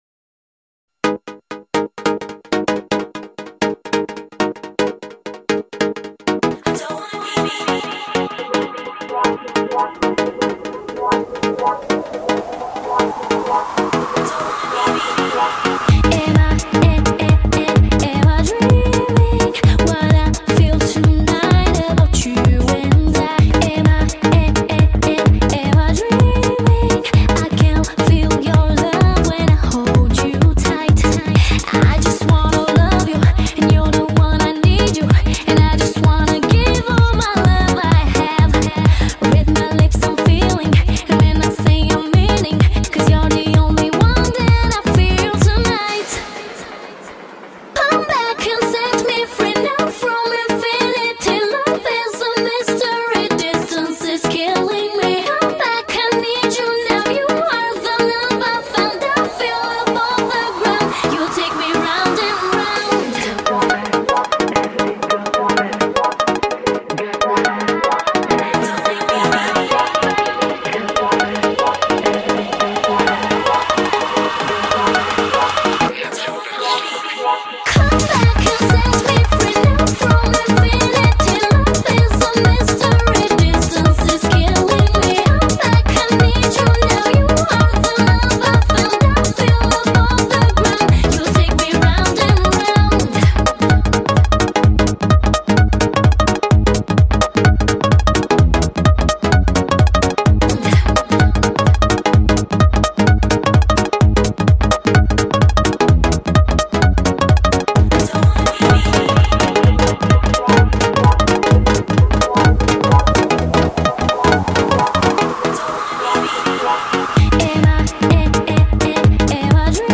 Жанр:Club/Dance